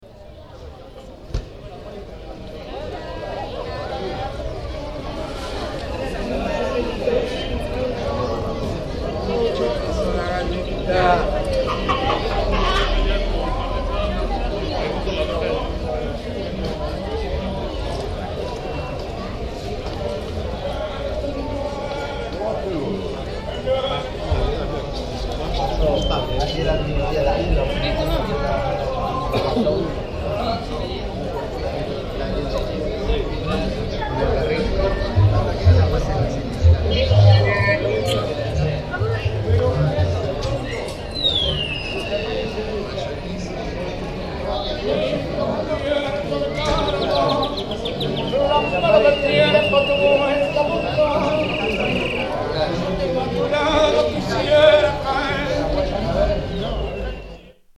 San Telmo Market soundwalk